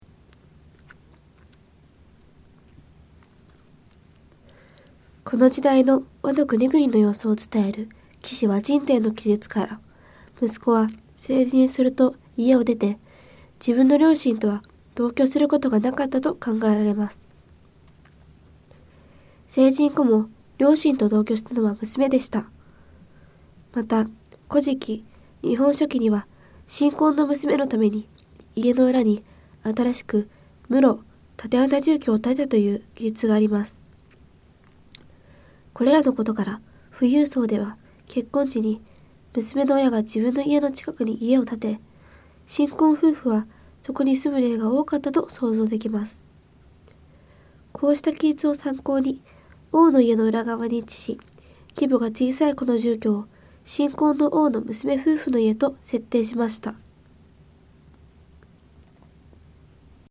こうした記述を参考に、「王」の家の裏側に位置し、規模が小さいこの住居を新婚の「王」の娘夫婦の家と設定しました。 音声ガイド 前のページ 次のページ ケータイガイドトップへ (C)YOSHINOGARI HISTORICAL PARK